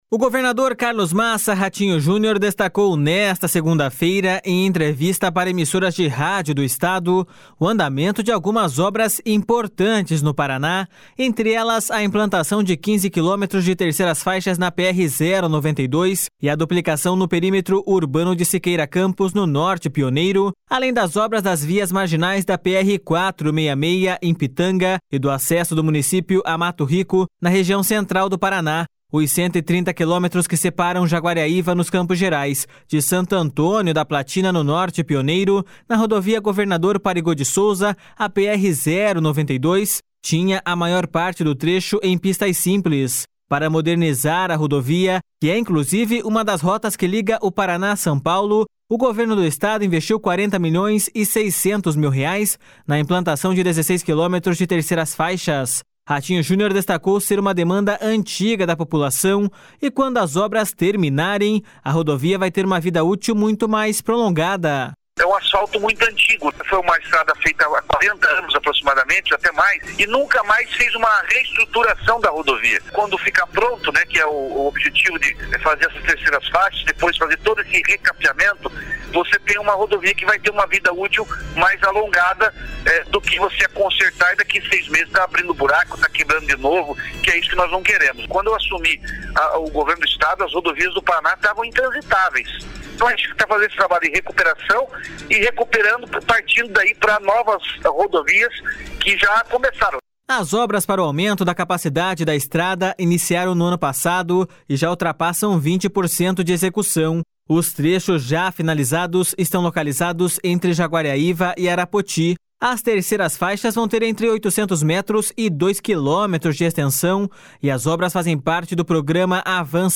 //SONORA RATINHO JUNIOR//